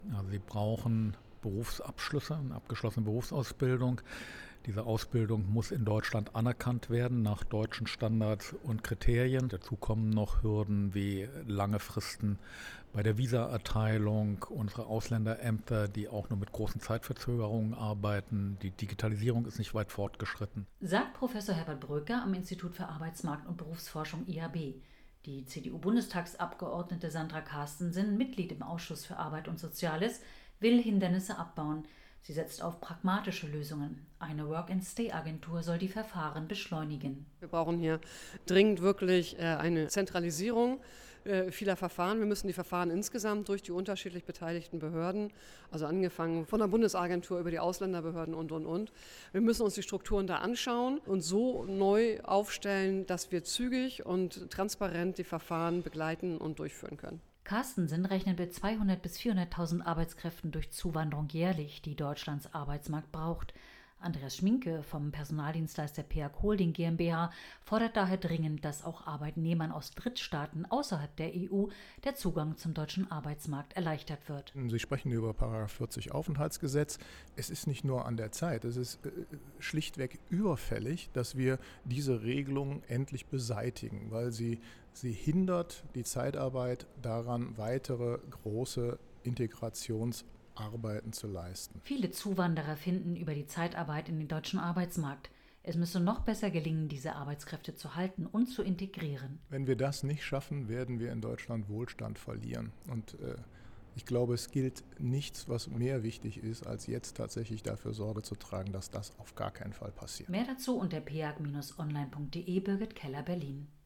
Die CDU-Bundestagsabgeordnete Sandra Carstensen, Mitglied im Ausschuss für Arbeit und Soziales, will Hindernisse abbauen.